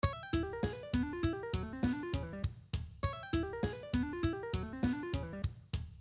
Exercise 6: Chromatic Diminished Pattern With Lots of Legatos (Ascending and Descending)
Lots Of Legatos here.
Chromatic-Diminished-Descending-String-Skipping-Exercises-v1.mp3